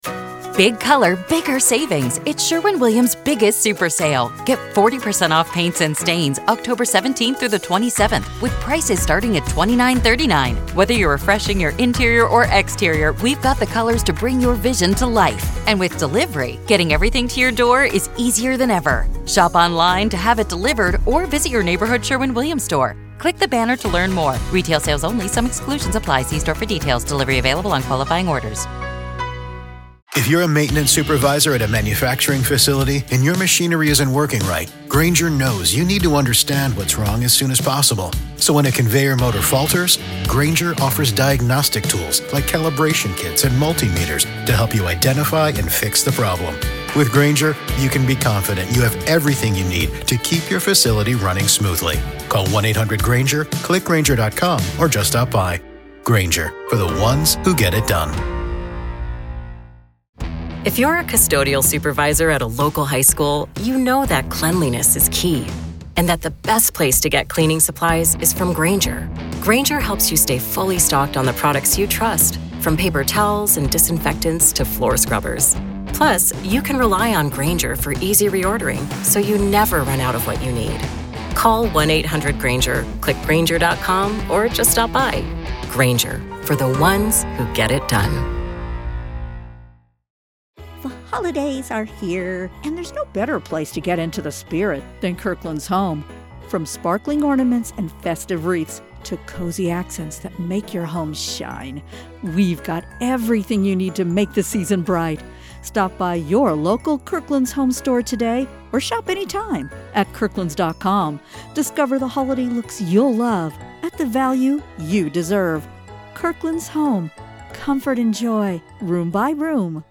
In this first segment, the Hidden Killers team sets the tone with a mix of sharp commentary and off-the-wall humor.
This opener is chaotic, funny, and unsettling, a snapshot of how dark crime stories bleed into the absurd.